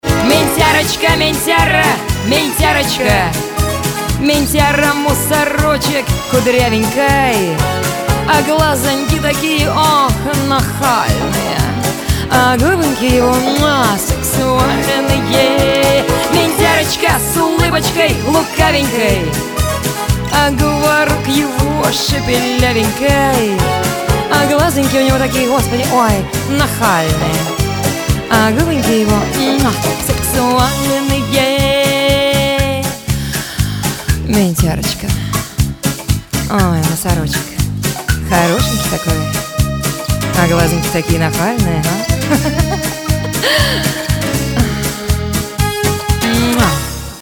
Нарезки шансона